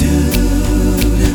D Vcl Swing 178-D.wav